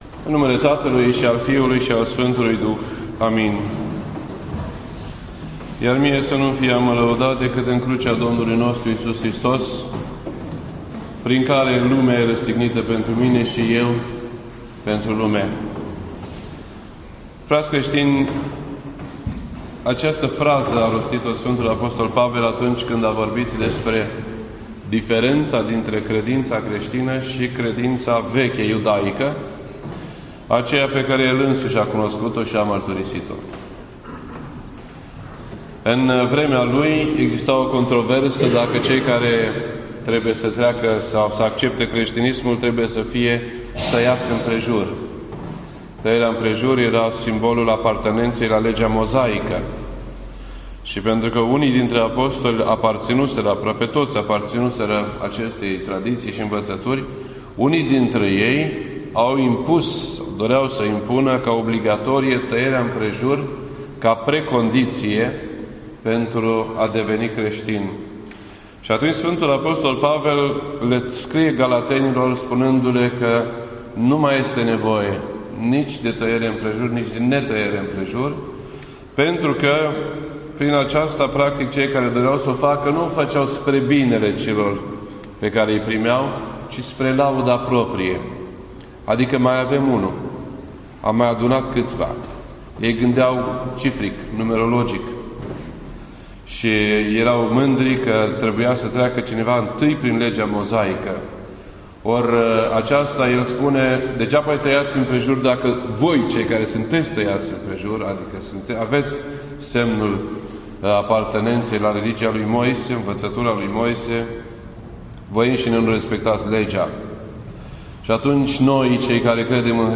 This entry was posted on Sunday, September 7th, 2014 at 12:16 PM and is filed under Predici ortodoxe in format audio.